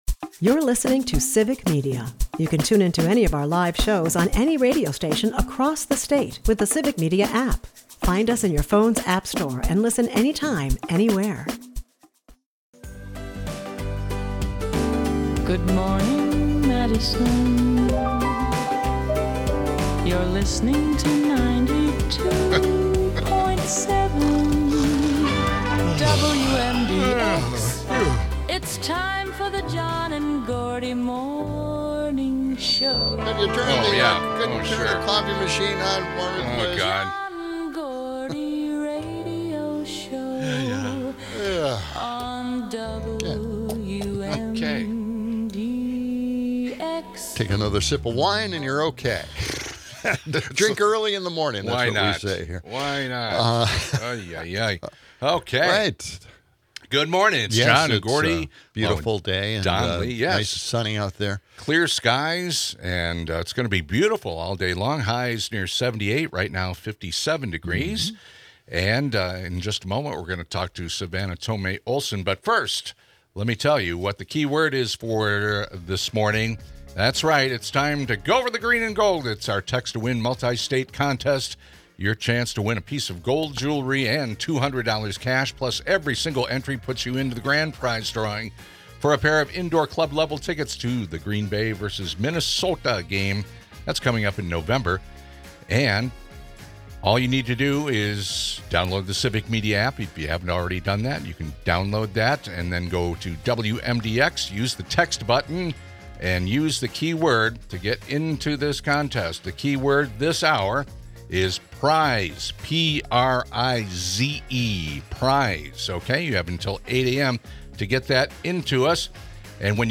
Meanwhile, Trump’s new $100,000 H1B visa fee sends shockwaves through tech giants like Amazon, as potential talent may seek refuge in more welcoming countries. Hosts and callers dive into political chaos, including James Comey’s role in past elections and Stephen Miller's controversial proposals.